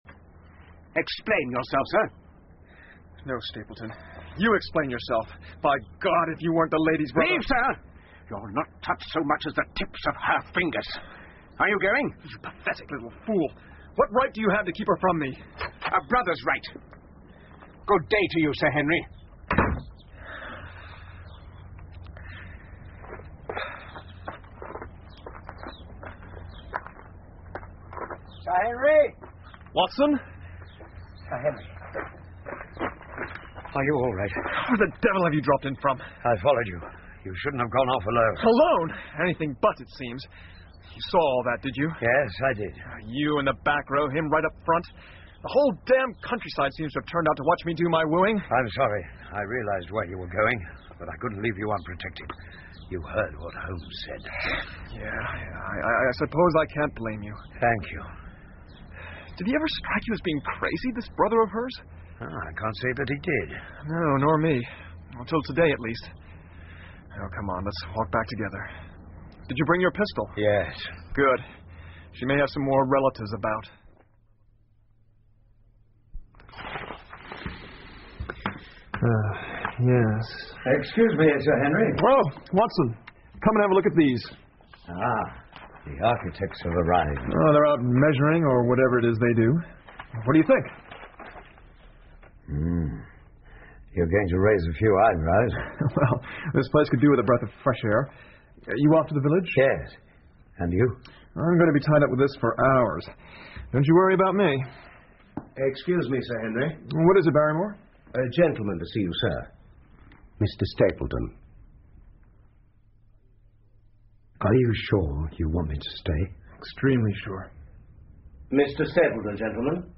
福尔摩斯广播剧 64 The Hound Of The Baskervilles - Part 02-5 听力文件下载—在线英语听力室